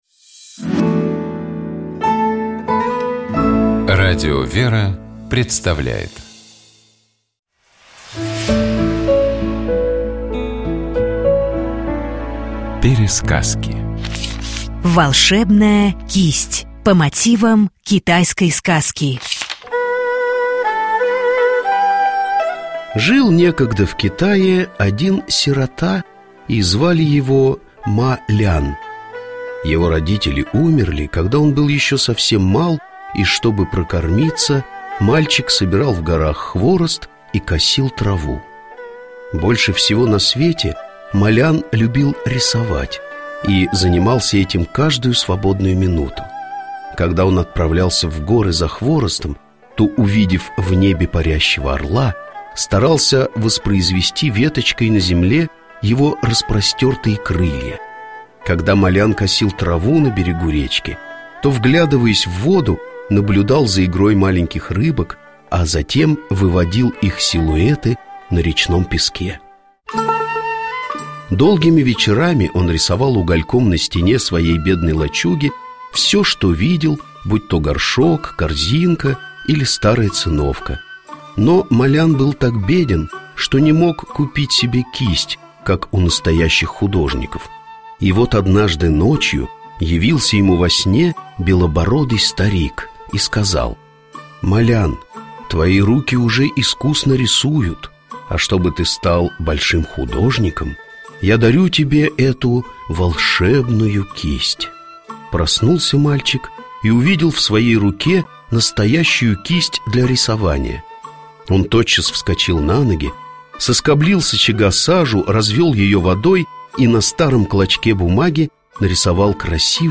4 мая Святейший Патриарх Московский и всея Руси Кирилл совершил Божественную литургию в кафедральном соборе во имя святых Жён-мироносиц в городе Баку.